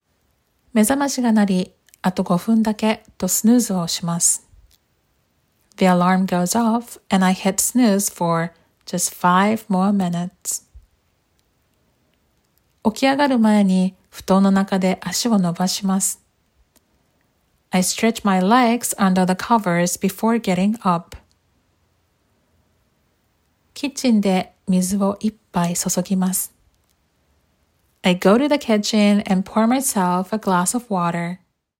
※フレーズ集の音声は英語のみ和訳＋英語をダウンロードできます。